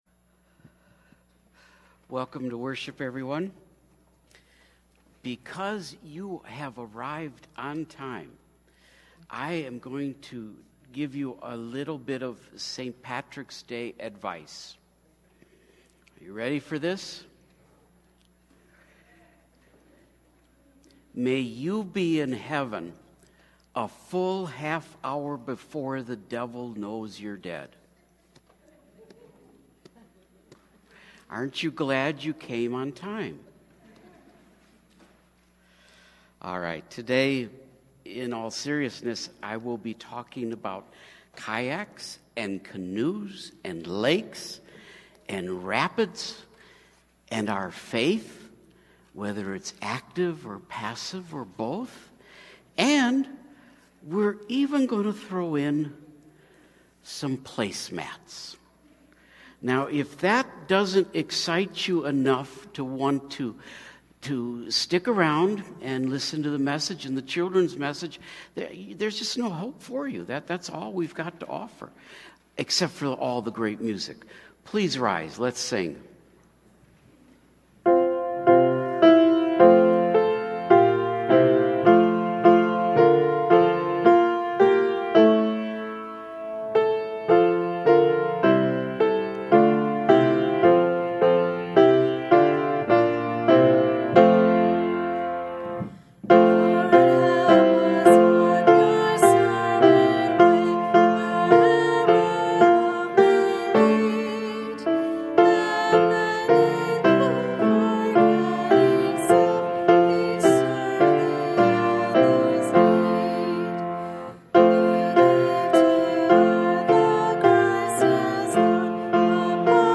Mar 25 / Worship & Praise – Willing vs. Unwilling – Lutheran Worship audio